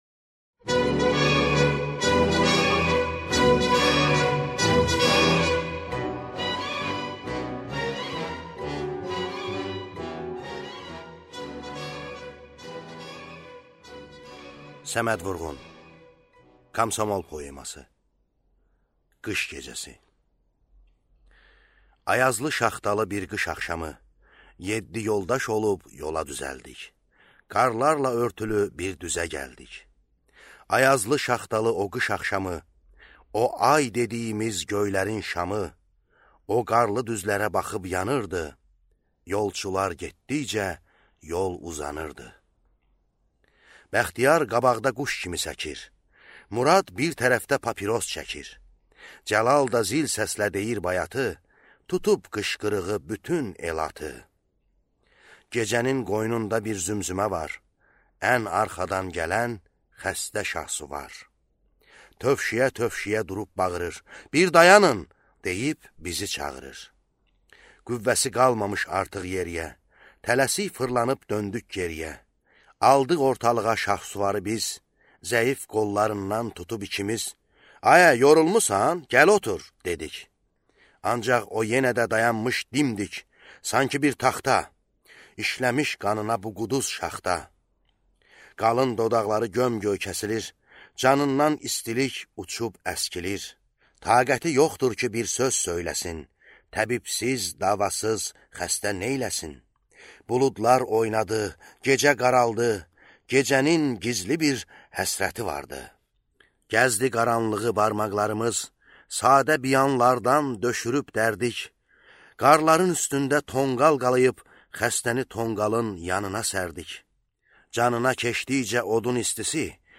Аудиокнига Komsomol | Библиотека аудиокниг